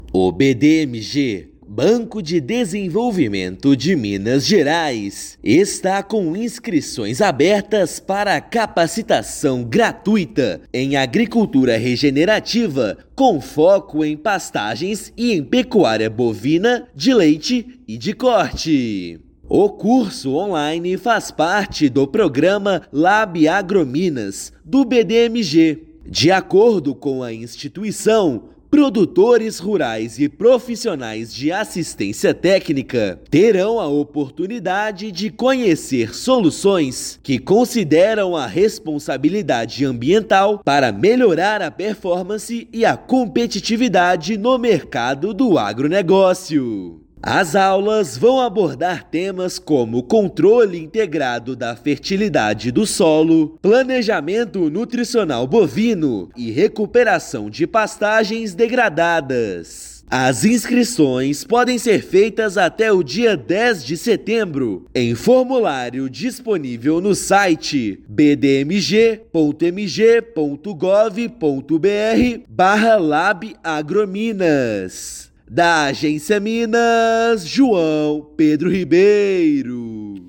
[RÁDIO] BDMG abre inscrições para capacitação gratuita em agricultura regenerativa com foco em pecuária bovina e pastagens
Produtores rurais e profissionais de assistência técnica de Minas Gerais podem se inscrever até 10/9 e curso começa no dia 16/9. Ouça matéria de rádio.